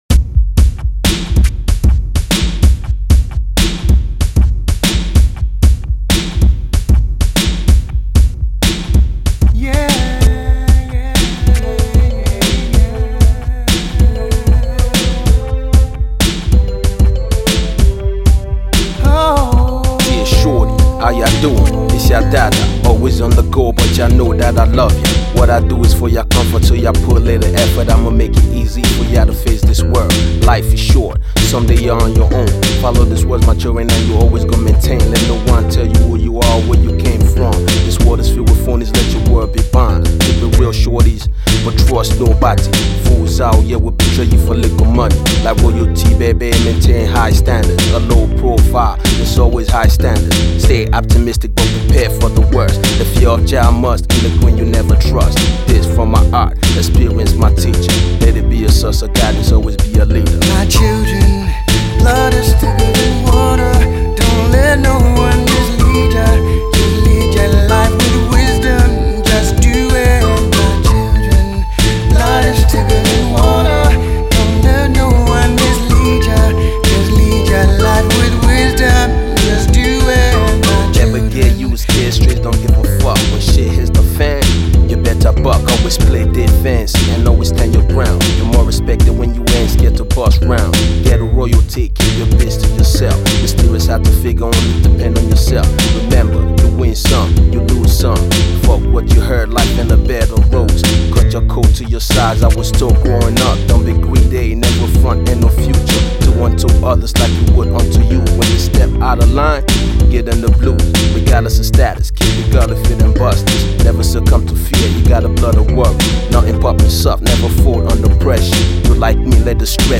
Drum & bass
Hardcore
Rap